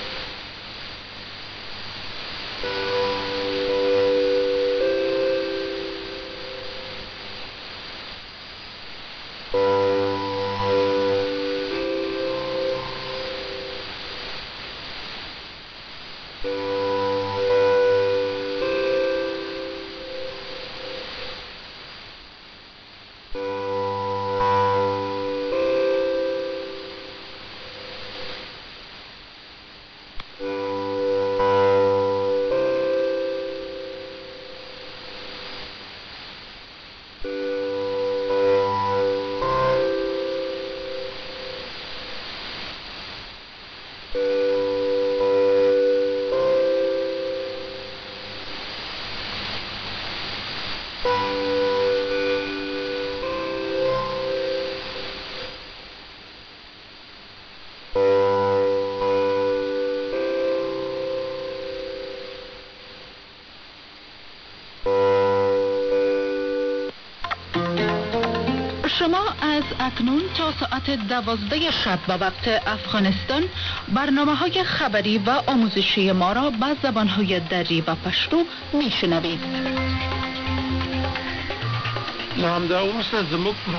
Then, there were the three notes “B-B-C” in tonic scale.
The three-note B-B-C was observed on March 4, 2026, at 0028 UTC on 7445 kHz. This recording was made through a Kiwi SDR on the island of Cyprus.